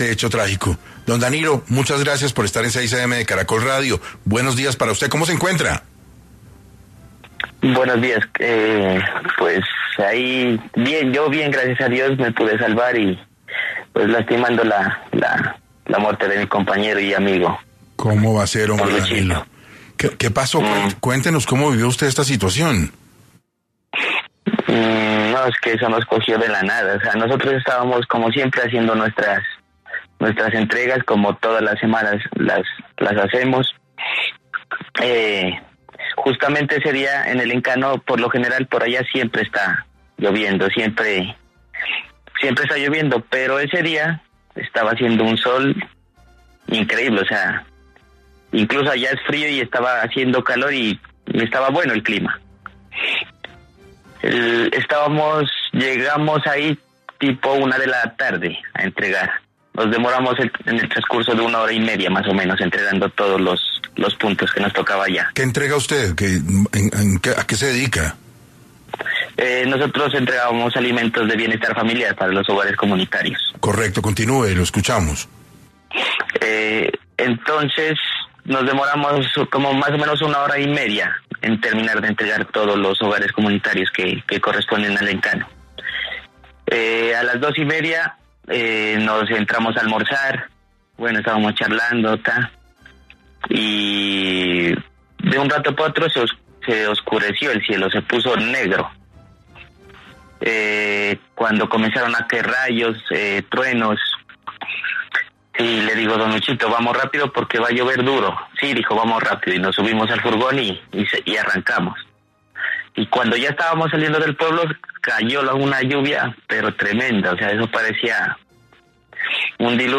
Hoy, en entrevista para 6AM, él nos narra la odisea que tuvo que vivir: